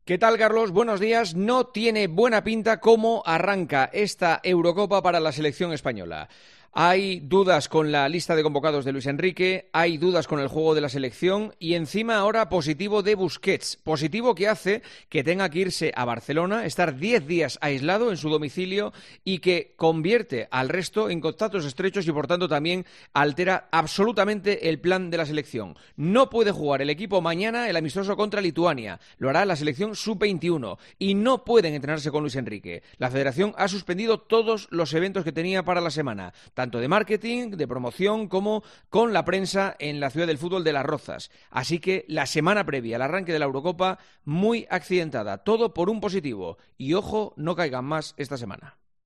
Juanma Castaño analiza la actualidad deportiva en 'Herrera en COPE'